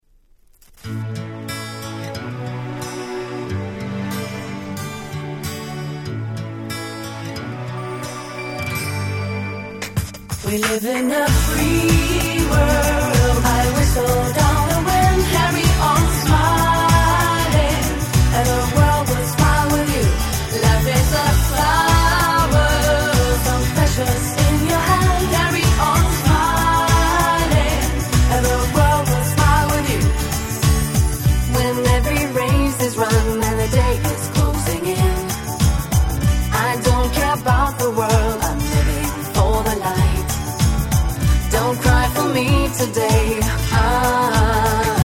鉄板キャッチーPop R&B♪
タイトル通り華やかでPopな曲調！